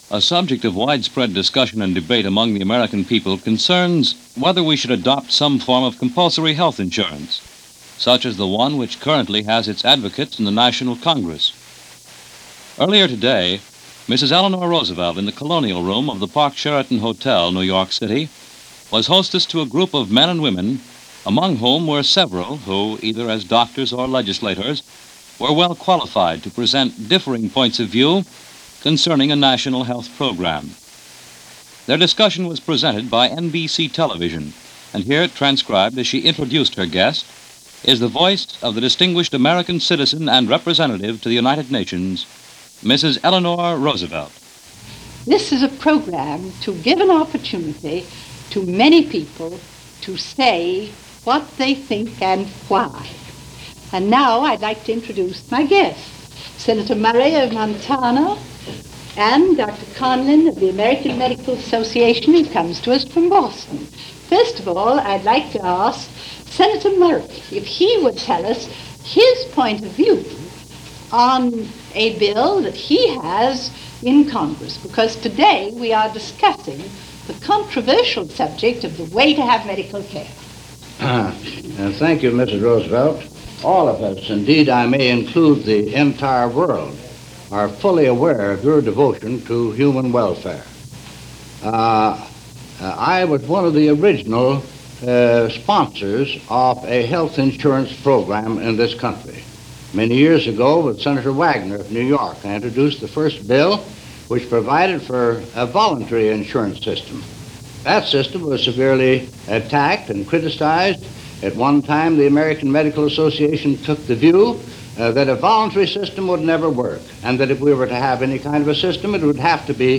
Eleanor Roosevelt Discusses A National Health Care Plan - 1950 - Past Daily Reference Room - NBC Radio and Televsion - Feb. 19, 1950